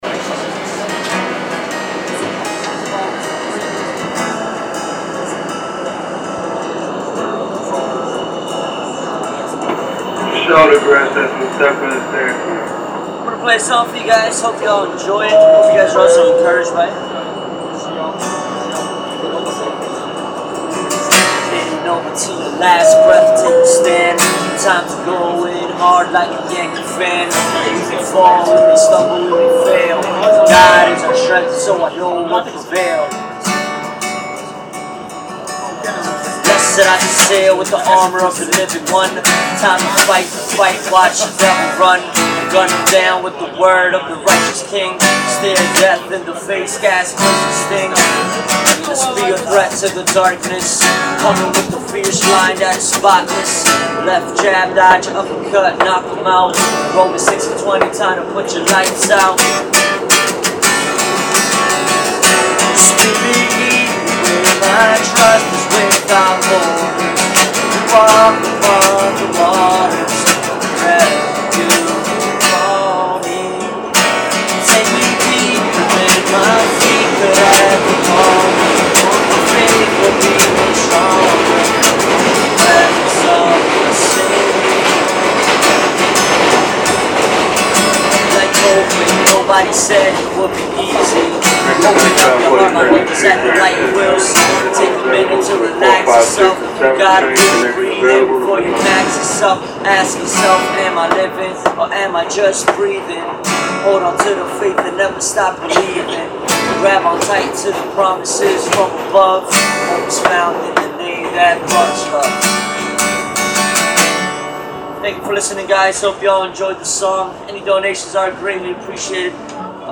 Song Heard on the Shuttle Train
I accidentally caught this subway performer when I left a voice recorder app running on my Galaxy Note.